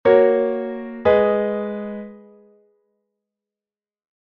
18_rota_en_Si_M.mp3